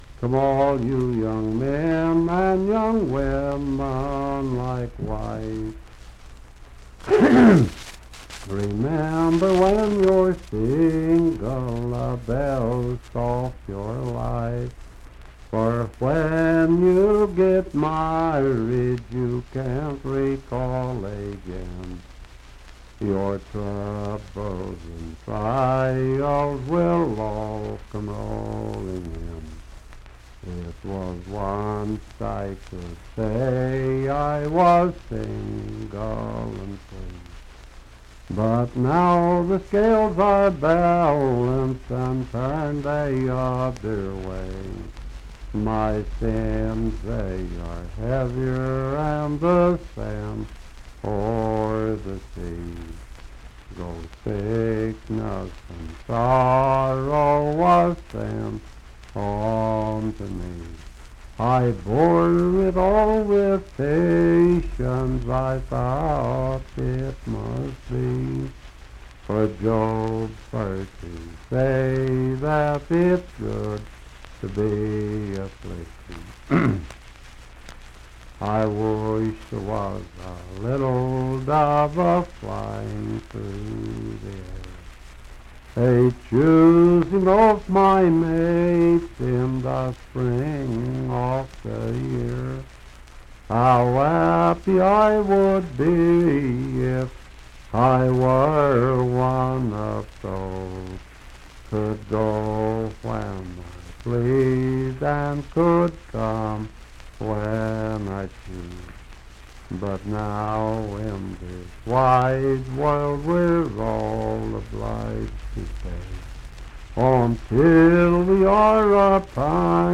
Unaccompanied vocal music
Verse-refrain 4d(4).
Voice (sung)
Fairview (Marion County, W. Va.), Marion County (W. Va.)